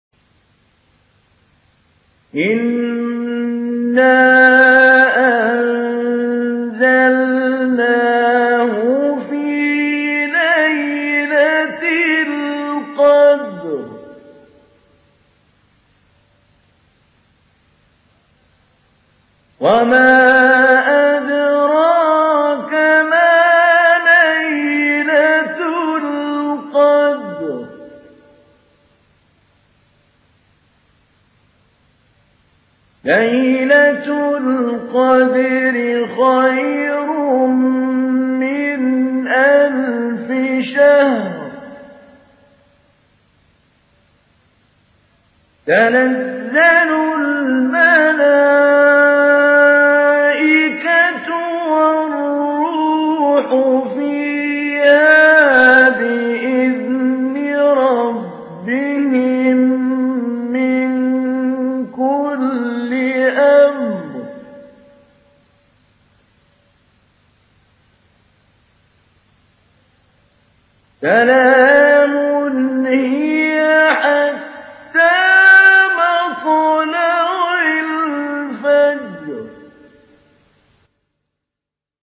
سورة القدر MP3 بصوت محمود علي البنا مجود برواية حفص
تحميل سورة القدر بصوت محمود علي البنا